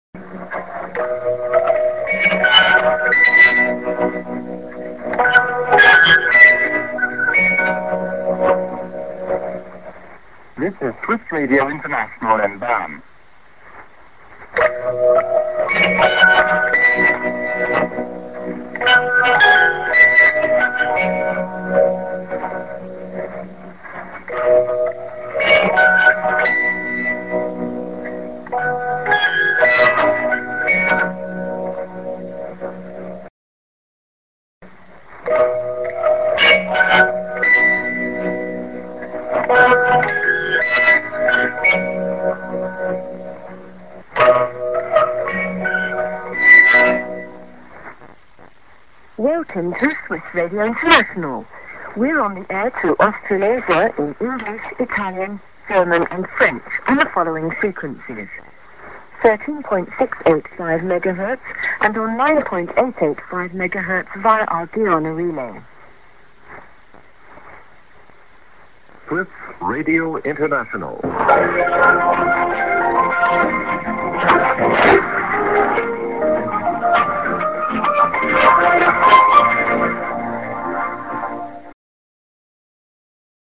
ST IS+10":ANN(man:ID)->IS->48":ID(women)->ID(man)